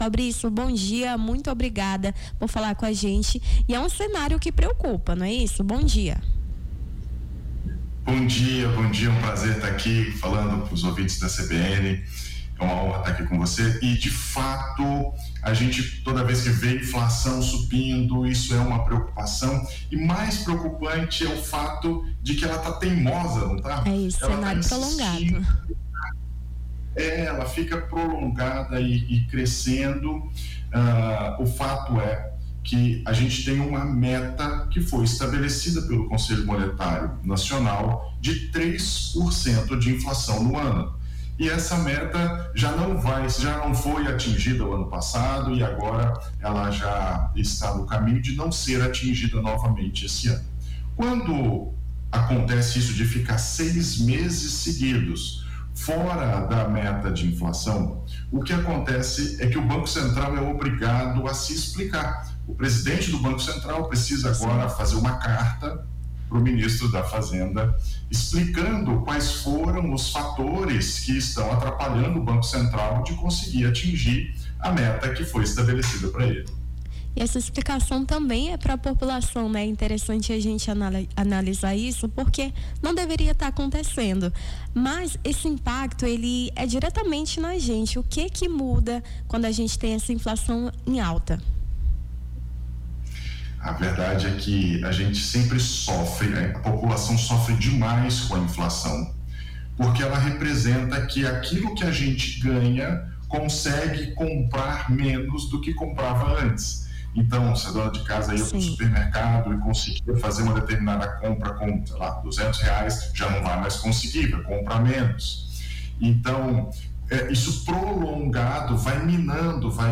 Nome do Artista - CENSURA - ENTREVISTA (IMPACTO POLITICA MONETARIA) 10-07-25.mp3